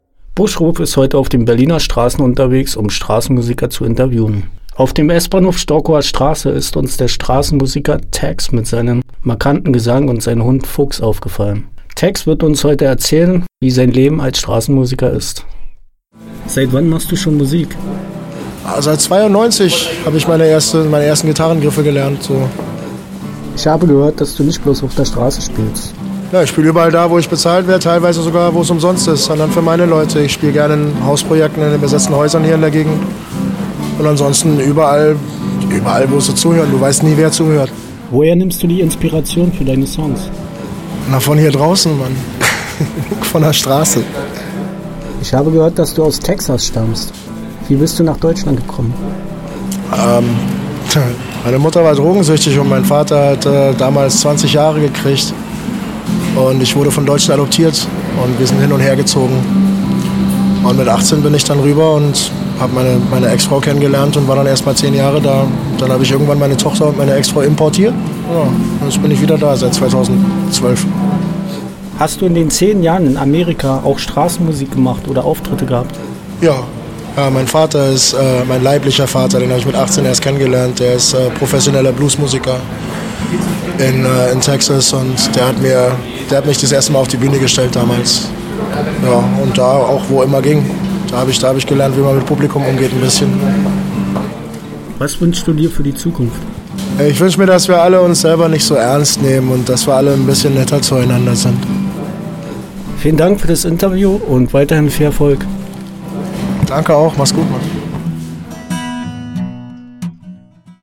haben mit dem Musiker gesprochen und auch gleich ein paar Lieder live aufgenommen. Er spricht über Straßenmusik, sein Leben in Amerika, wo er erste Bühnenerfahrungen mit seinem Vater hatte u.v.m.